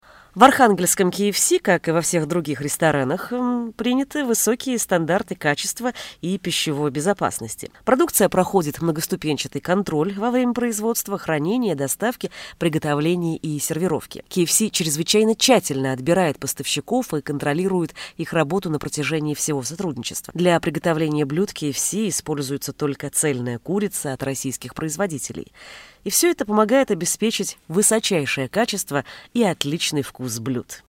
Микрофон Samson C01U PRO, условия для записи есть.
Демо-запись №2 Скачать